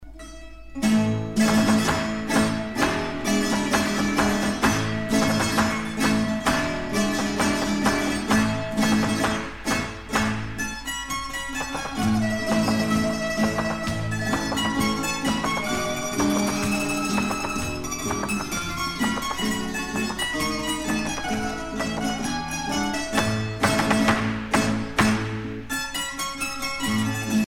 danse : farruca
Pièce musicale éditée